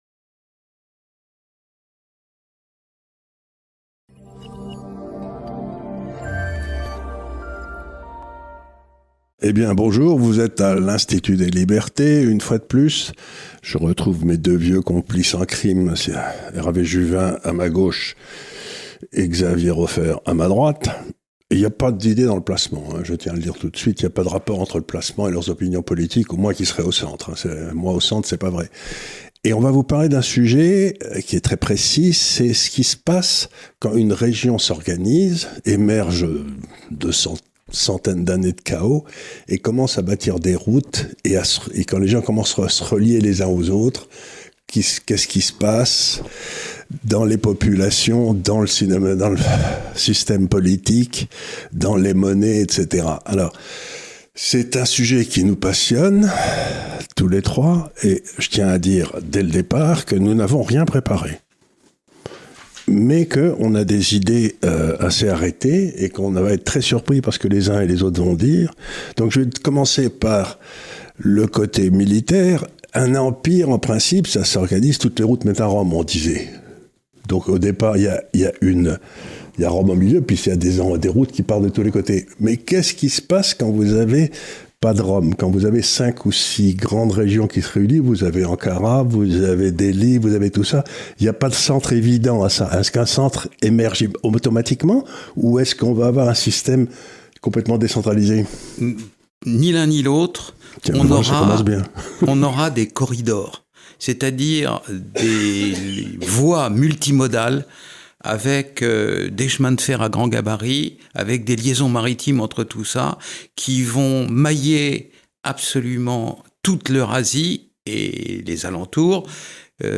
#145 - Inde, Chine, Belt and Road que prévoir pour demain? Charles Gave reçoit Hervé Juvin et Xavier Raufer
Dans cette capsule de géopolitique, Xavier Raufer (Criminologue) et Hervé Juvin (Homme d'affaires et député Européen) nous parlent du développement des flux essentiels à venir entre les régions Inde, Chine , Eurasie.